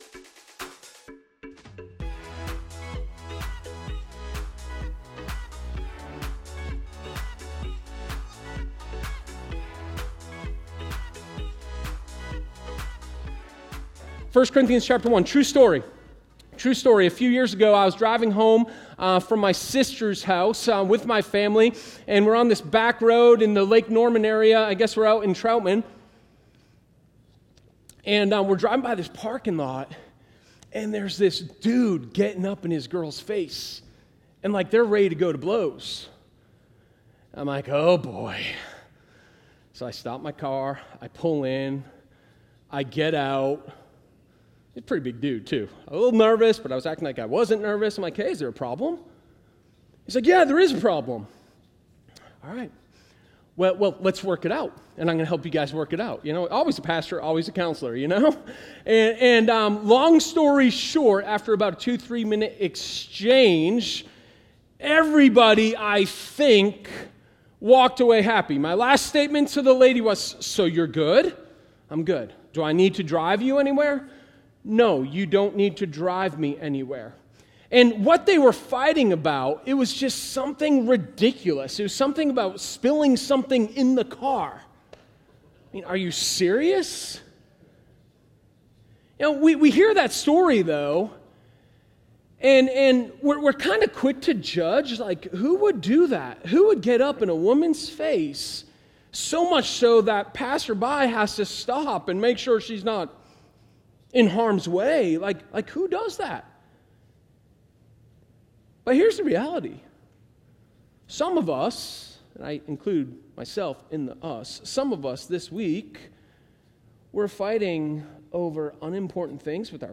Sermon09_26_How-to-Win-a-Fight.m4a